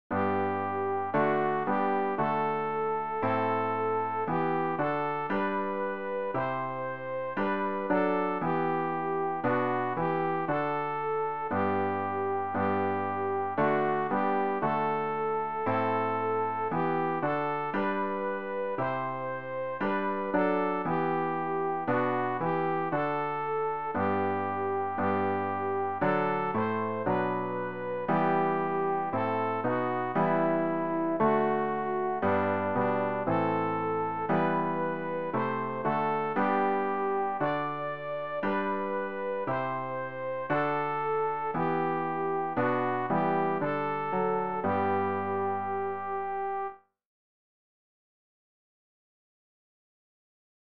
sopran-rg-084-aus-tiefer-not-schreie-ich-zu-dir-strassburg.mp3